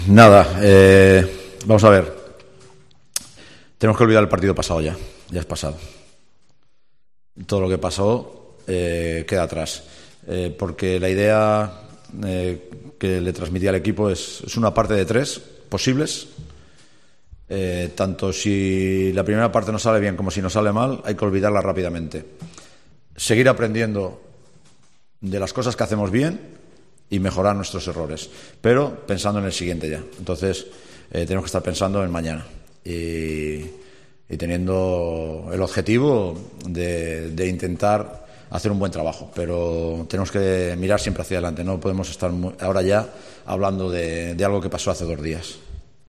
El entrenador de Unicaja para revista en la previa del decisivo partido ante el Alba de Berlín.